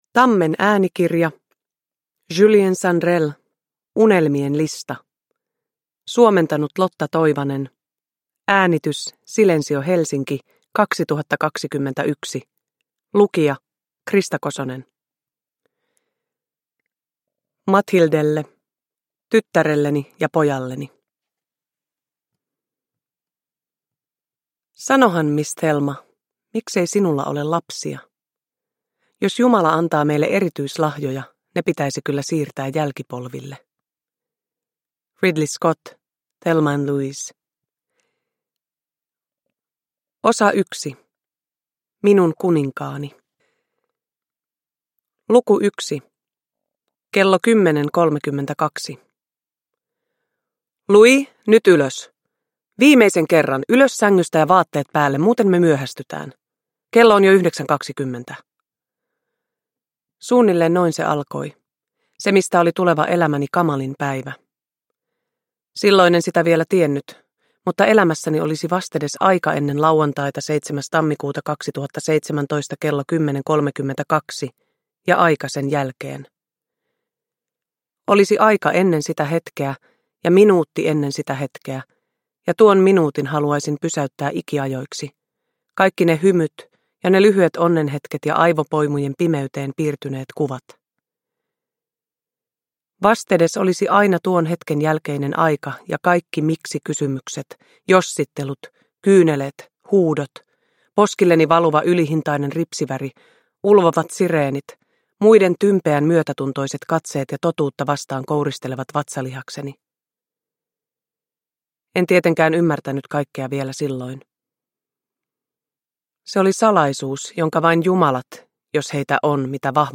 Uppläsare: Krista Kosonen